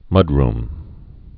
(mŭdrm, -rm)